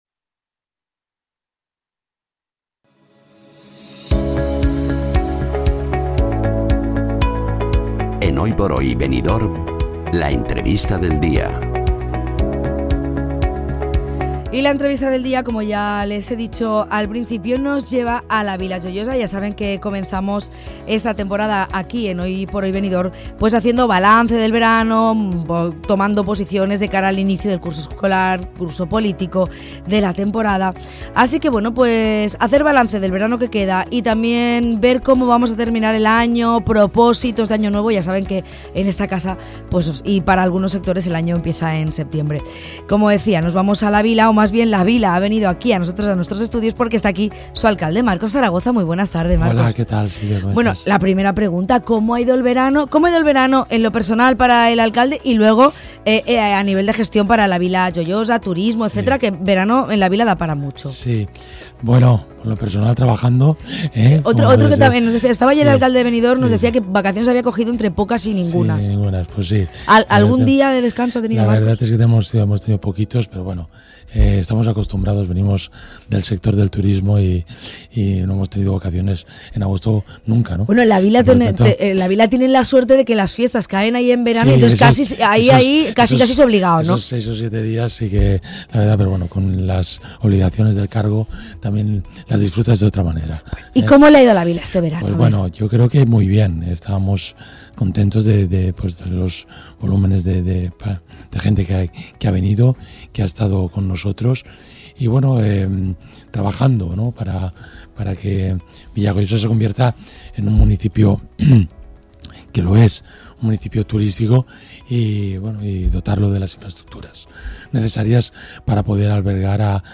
Entrevista a Marcos Zaragoza, alcalde de La Vila Joiosa